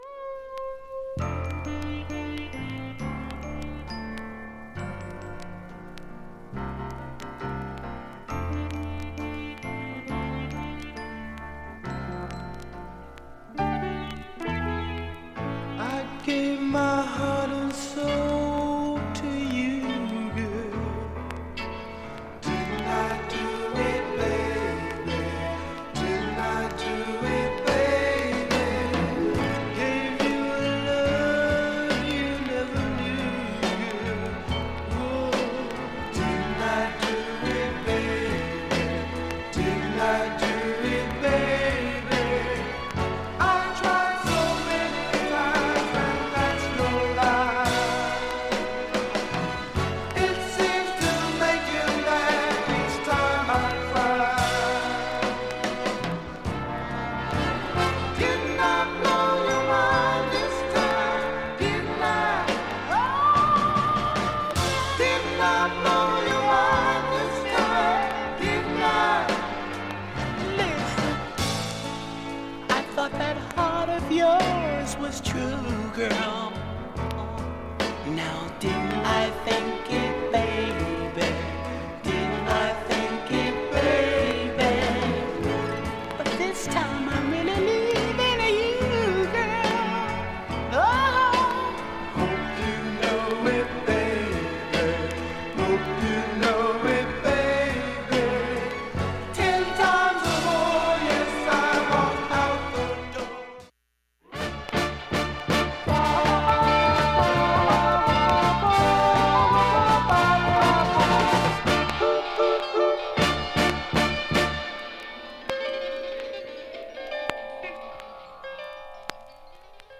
無音部もチリ無く音質良好全曲試聴済み。
周回プツ出ますがかすかです。
2,(1m44s〜)A-2終りにかすかな12回プツ
心臓の音のようなプツ15回出ます
８回までのかすかなプツが１箇所
６回までのかすかなプツ6箇所